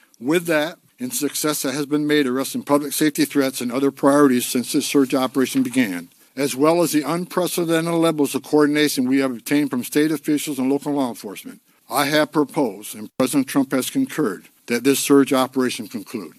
White House Border Czar Tom Homan said today they’ve seen a notable decrease in unlawful agitator activity in Minneapolis and throughout the state: